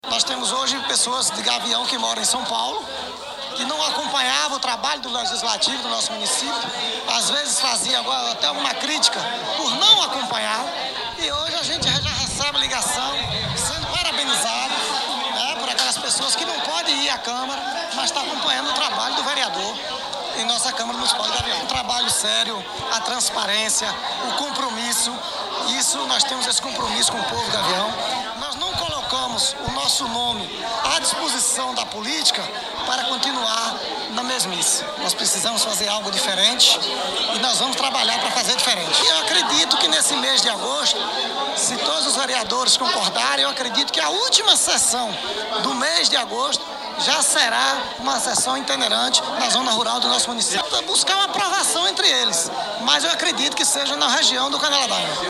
Foi formada uma pequena carreata com direito a carro de som anunciando a novidade e parou ao lado do centro de abastecimento onde aconteceram os discursos.